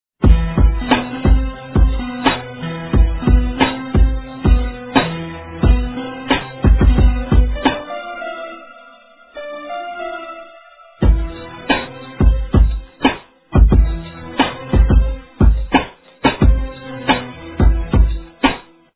И в качестве доказательства того что я пишу музыку предсавляю фрагмент бита с моей темы "Александра", голос мне записывать к сожелению негде( много пробовал микрофонов - качество плохое, микшер зашкаливает, потом труднгости при сведении, вообщем кто занимается муз. творчеством понимает)... ну теперь вроде точно всё, прошу прощение за качество(ограничение 50 кб- я не виноват всё сведено по науке)